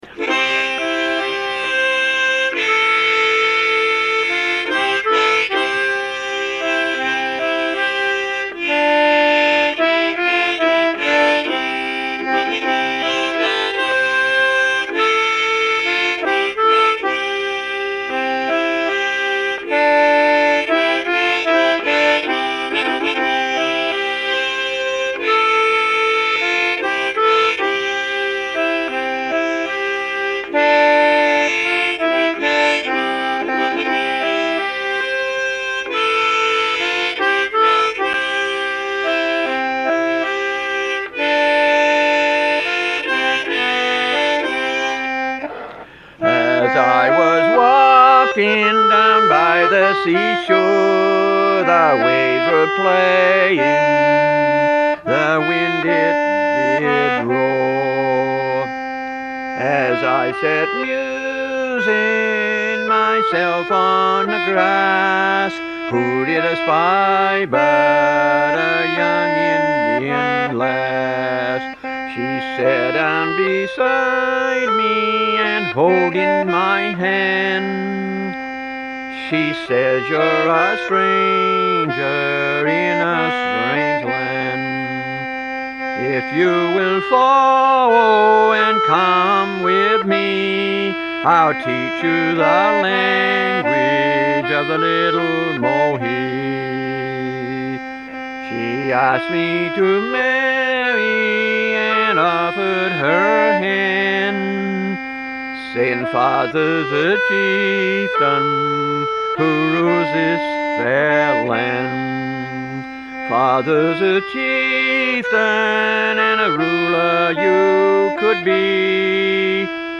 Town: Appleton, ME
harmonica and concertina
It is an old song performed by a musician playing solely for the enjoyment of himself and others.